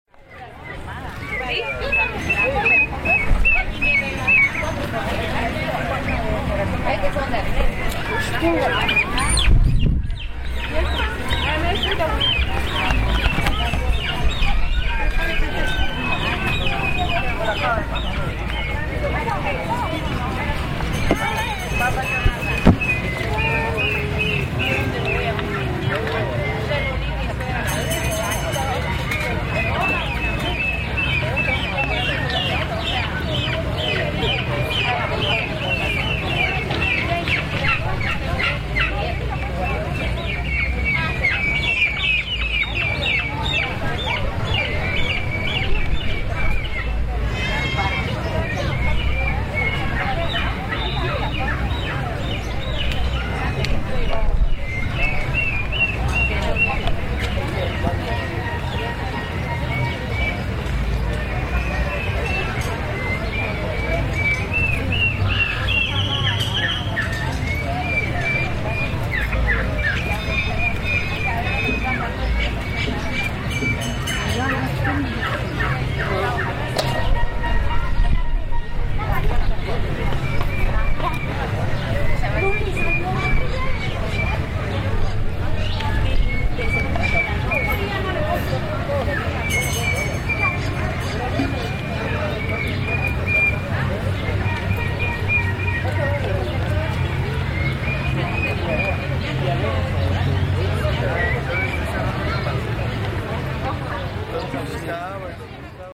Plaza en Berriozábal Uno
Los domingos son de plaza en Berriozábal, ahi en el parque central se reunen comerciantes para vender cosas que se producen en la región como plantas de ornato, frutas, legumbres, hamacas, dulces típicos, gallinas, macetas y otros productos no tradicionales.